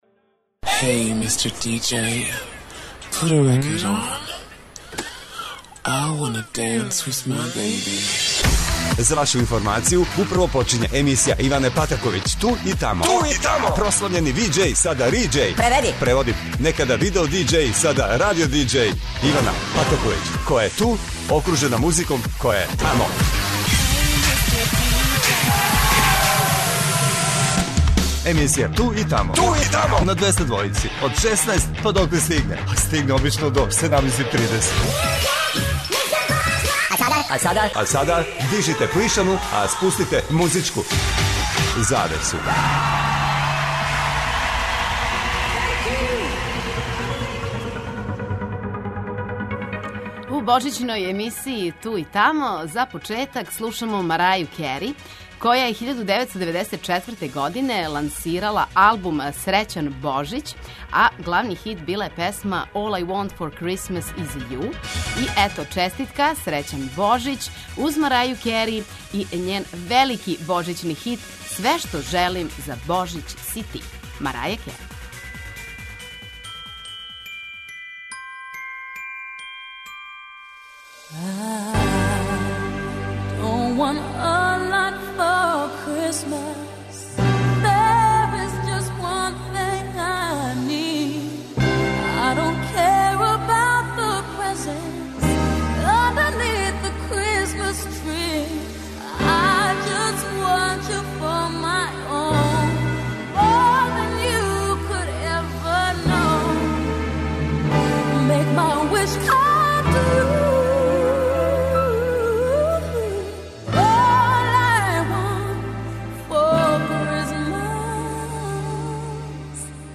преузми : 43.60 MB Ту и тамо Autor: Београд 202 Емисија Ту и тамо суботом од 16.00 доноси нове, занимљиве и распеване музичке теме. Очекују вас велики хитови, страни и домаћи, стари и нови, супер сарадње, песме из филмова, дуети и још много тога.